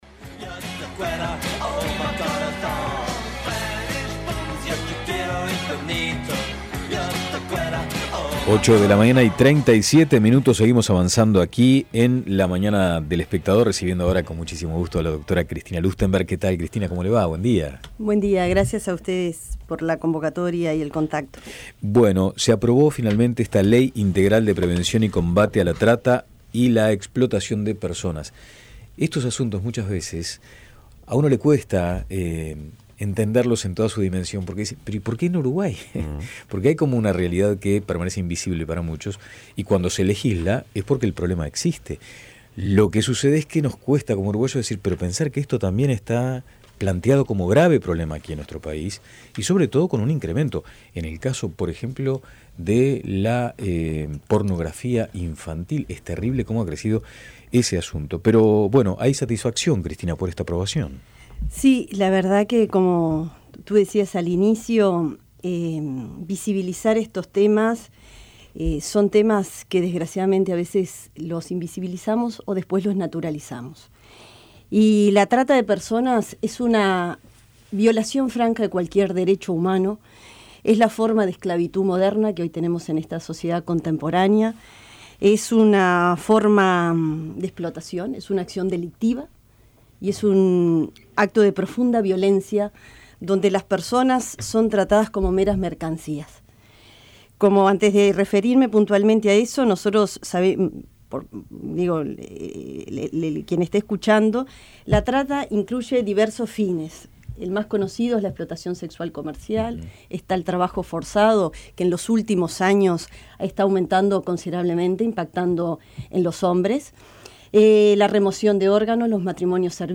Lustemberg destacó en La Mañana de El Espectador que solamente en 2017 aumentó un 35% la cantidad de mujeres uruguayas de entre 18 y 35 años involucradas en la explotación sexual comercial (350 casos), y que solamente unas 10 personas son procesados por estos delitos por año, cuando hay más de 1.000 involucrados.